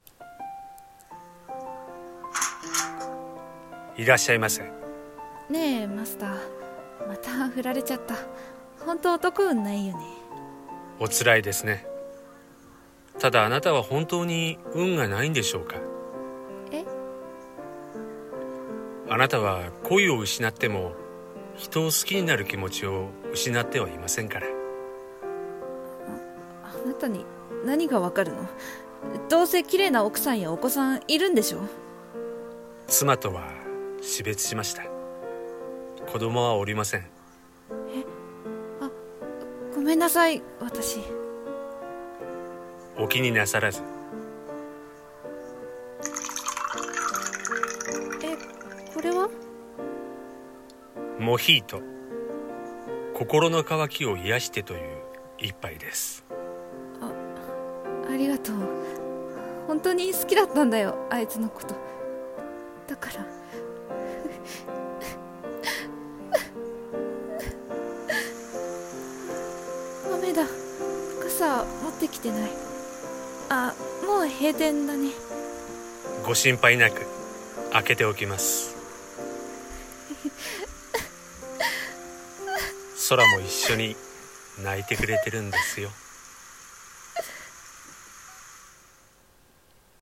2人声劇「BAR 🍸『kokoro』